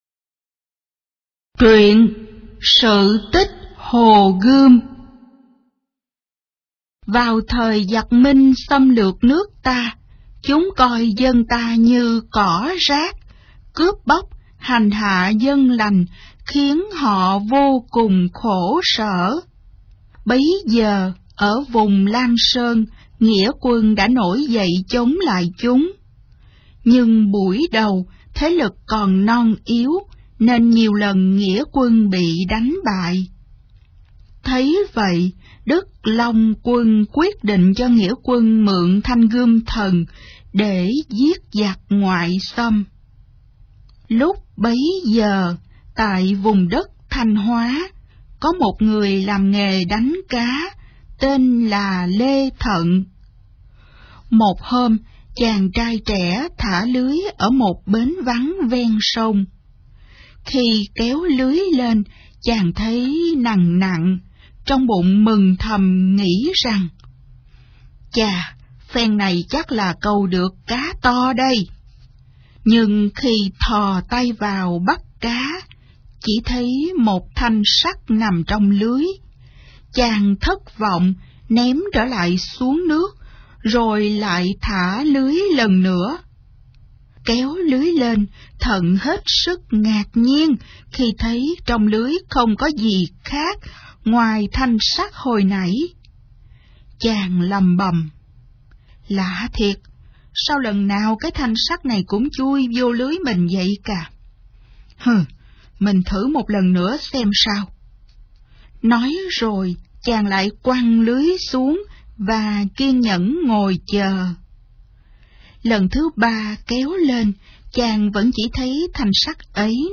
Sách nói | Truyện cổ tích Việt Nam. 11